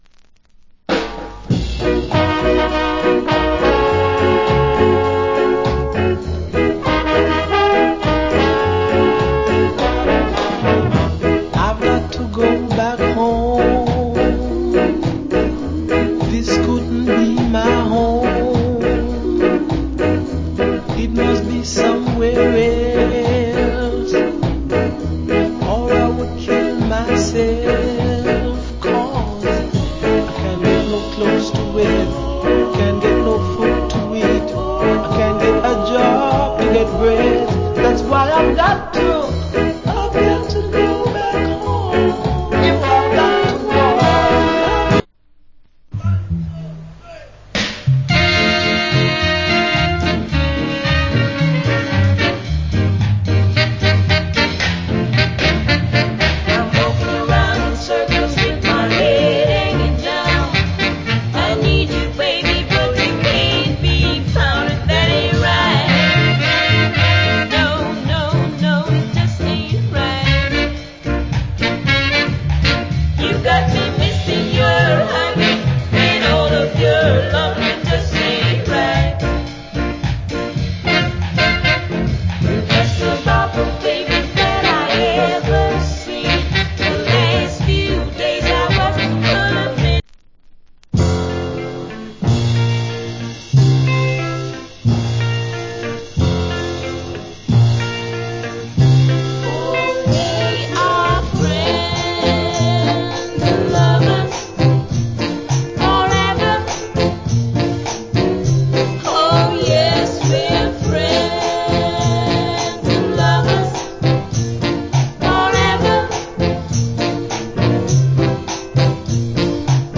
Great Ska To Rock Steady.